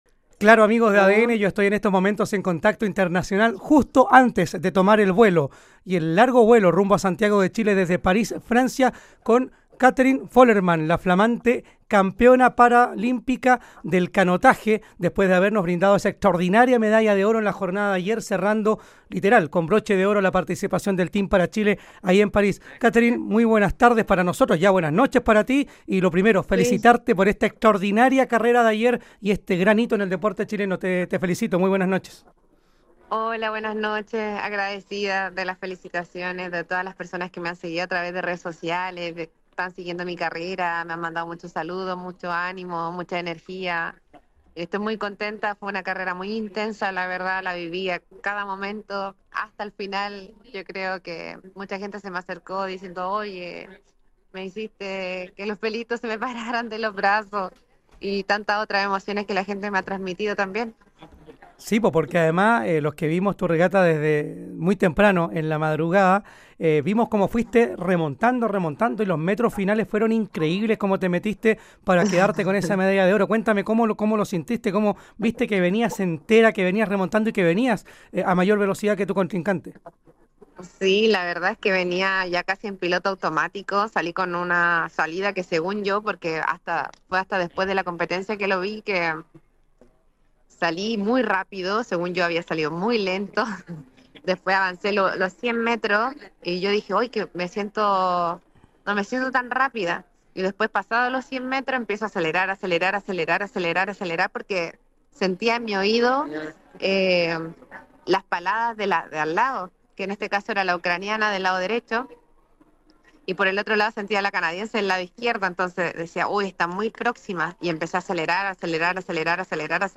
En conversación con ADN TOP KIA, la campeona del para canotaje remarcó lo rápido que se le terminó dando la competencia que le permitió quedarse con el título.